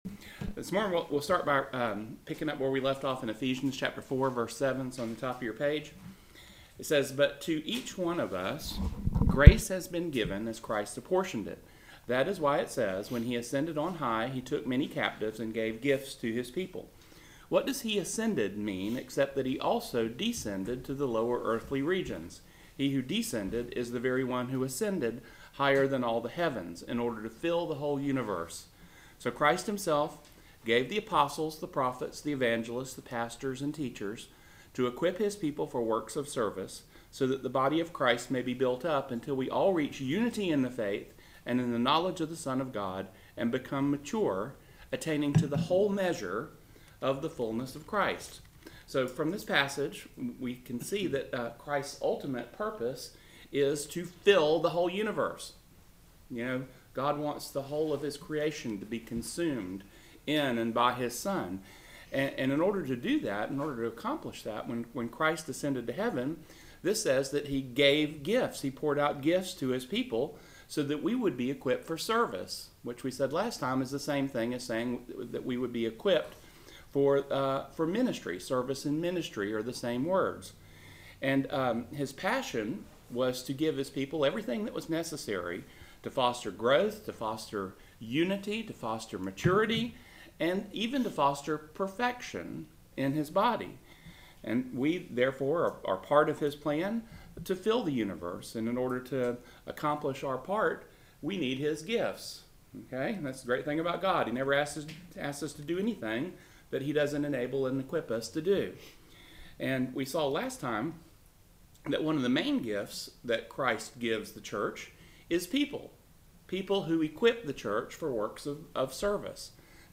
Bible Study 3/20/18 Enforcers of God’s Grace